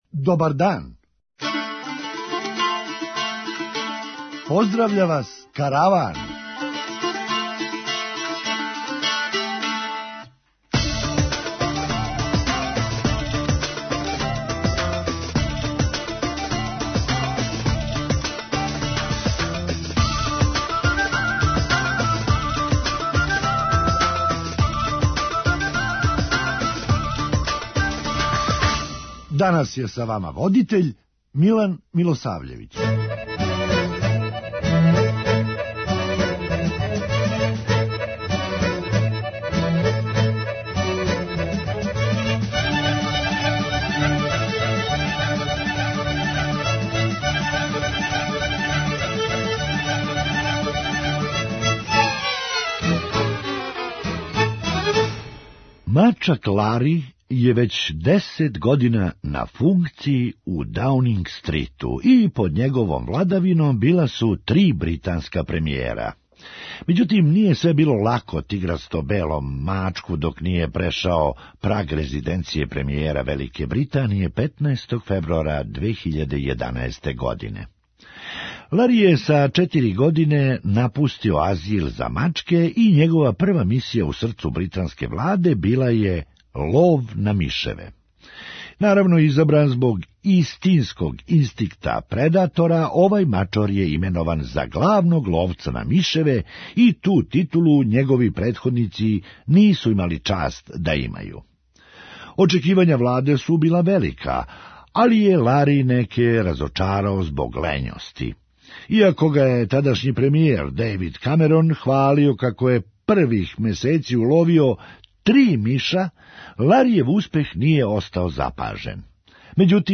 Хумористичка емисија
Не тражи да му саградите кућу, већ да понешто реновирате! преузми : 9.26 MB Караван Autor: Забавна редакција Радио Бeограда 1 Караван се креће ка својој дестинацији већ више од 50 година, увек добро натоварен актуелним хумором и изворним народним песмама.